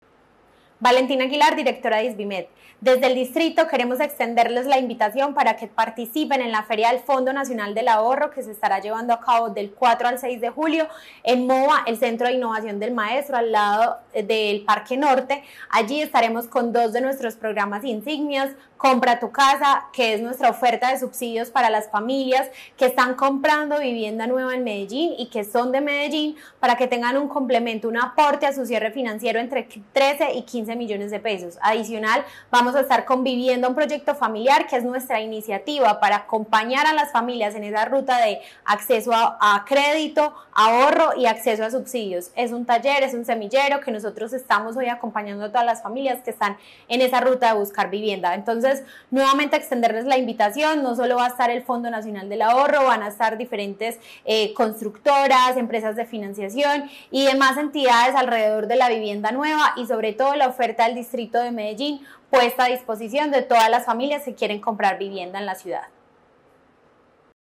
Declaraciones de la directora del Isvimed, Valentina Aguilar Ramírez.